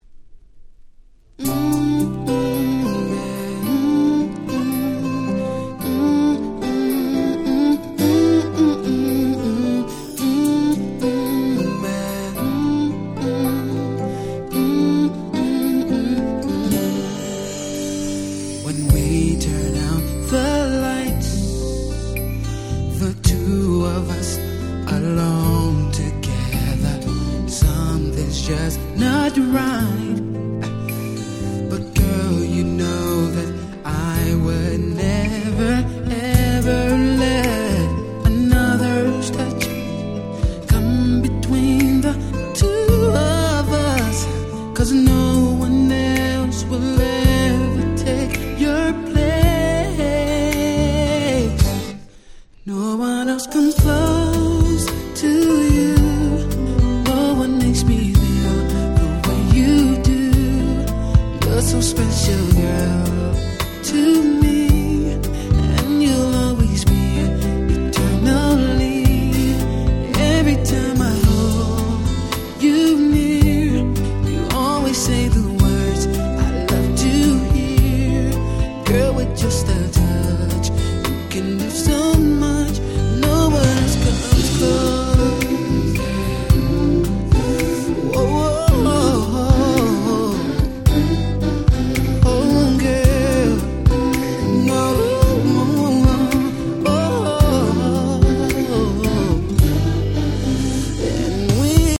98' Super Nice Slow Jam / Ballad !!!!!!